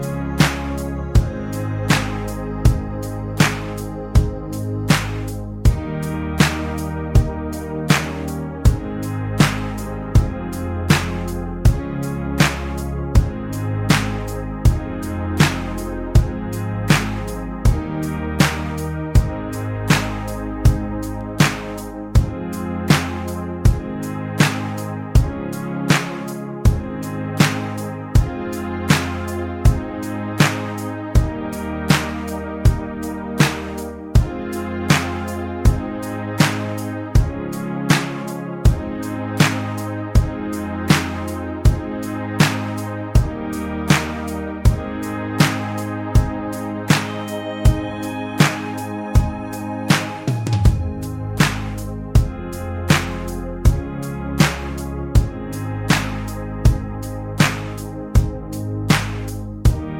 no Backing Vocals Guitars or bass Pop (2010s) 4:04 Buy £1.50